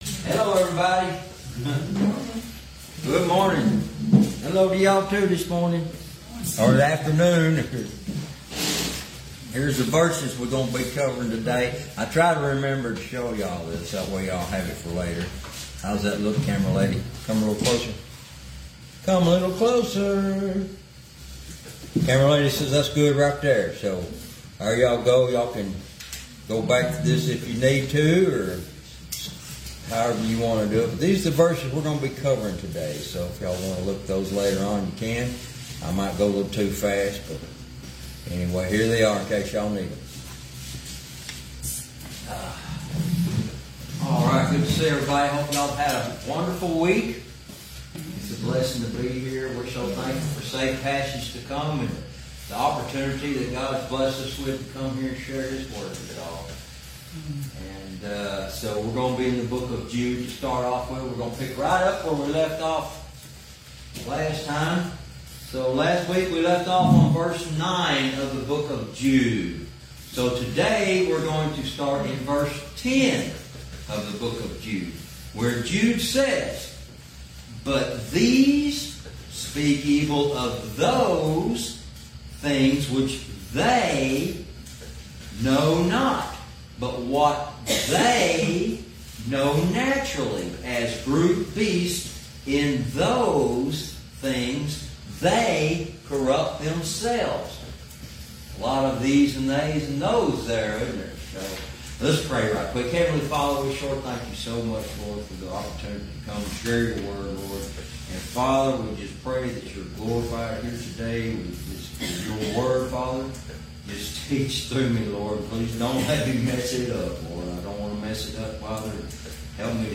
Verse by verse teaching - Lesson 33